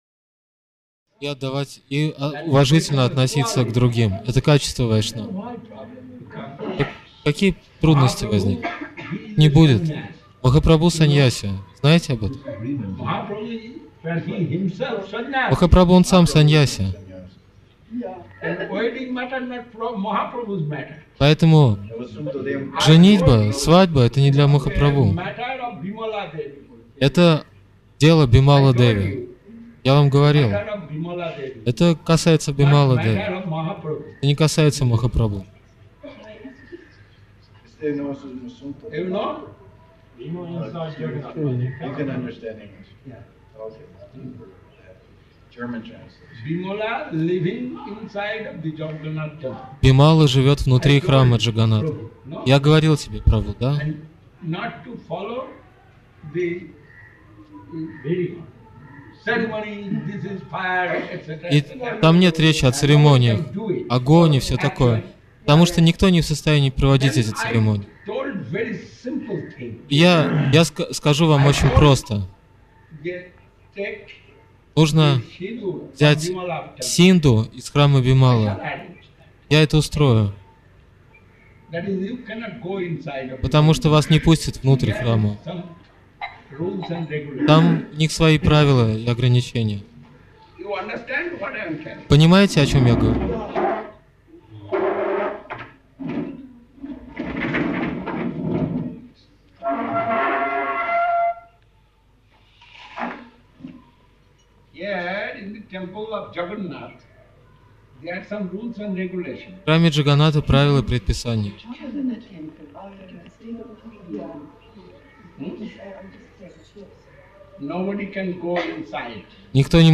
Разговор по телефону
Place: SCSMath Puri